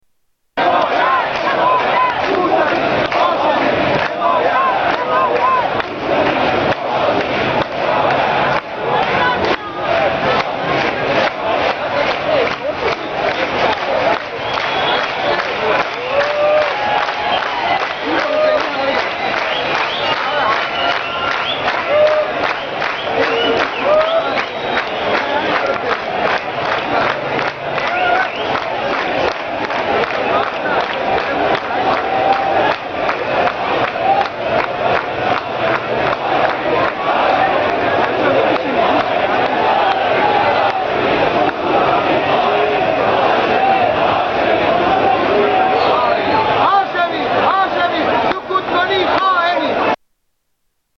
Riot in tehran streets